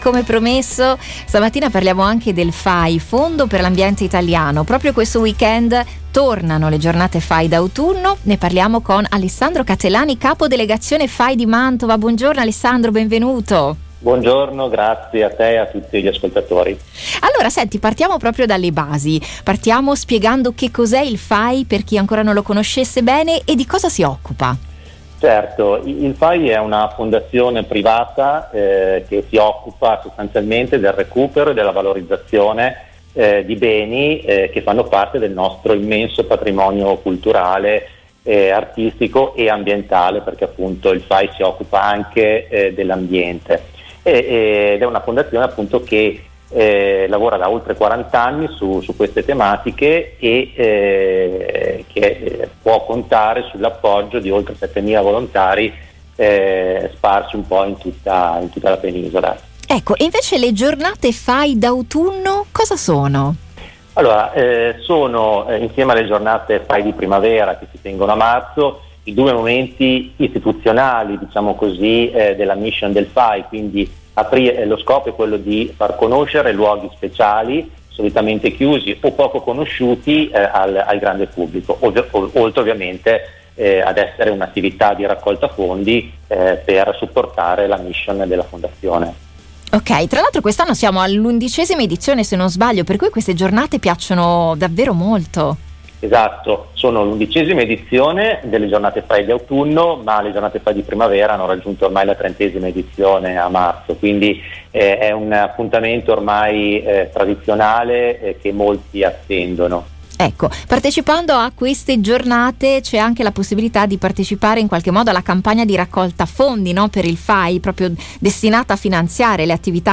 Al nostro microfono